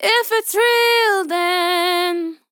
Vocal Sample
DISCO VIBES | dry
female
Categories: Vocals